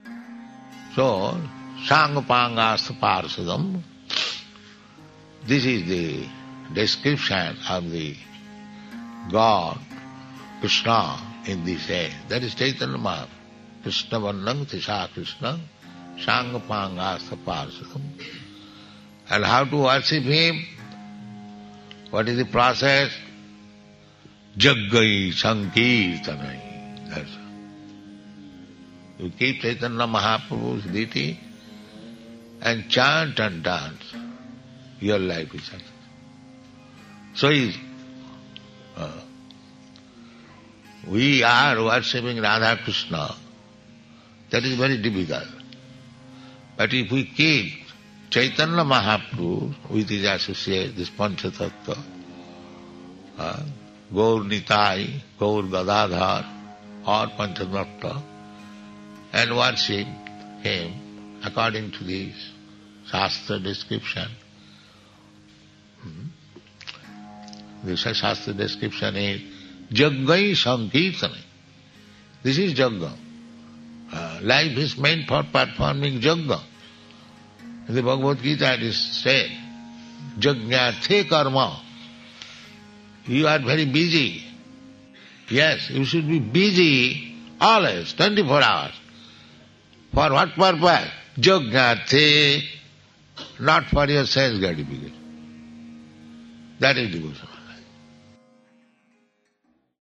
(750310 - Lecture BG 07.02 - London)